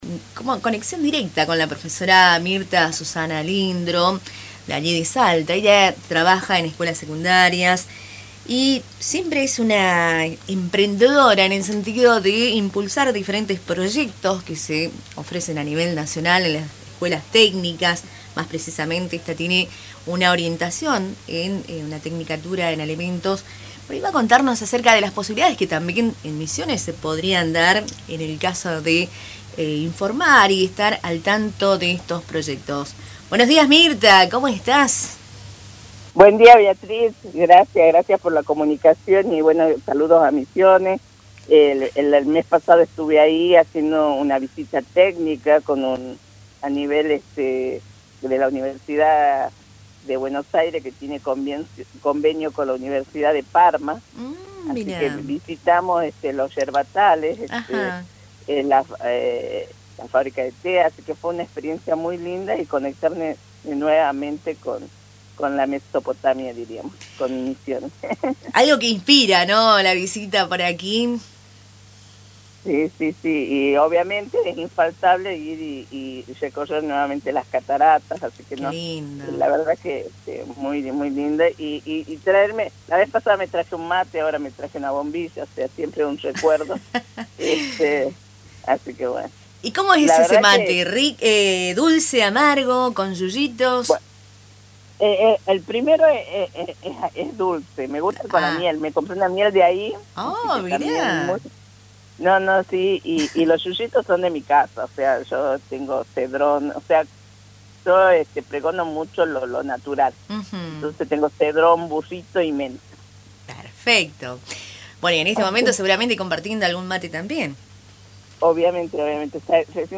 La entrevista completa en La FM 105.9